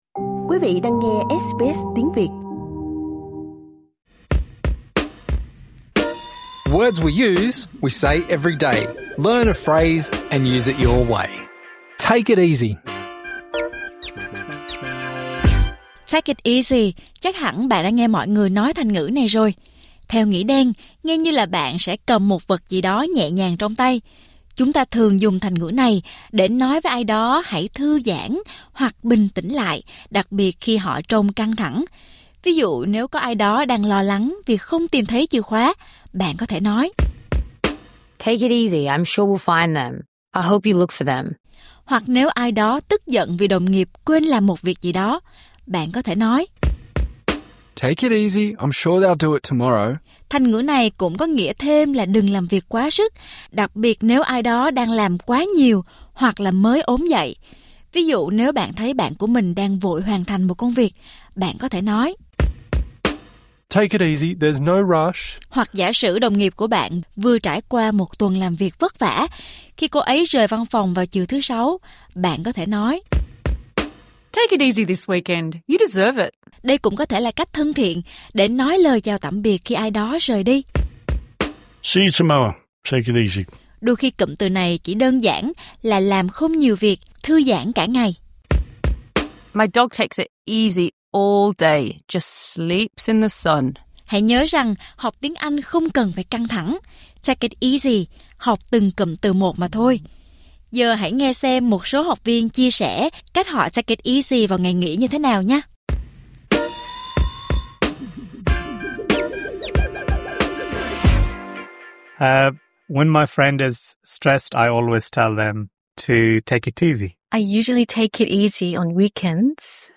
“Từ ngữ thông dụng” là một loạt chương trình song ngữ giúp bạn hiểu các thành ngữ như “take it easy".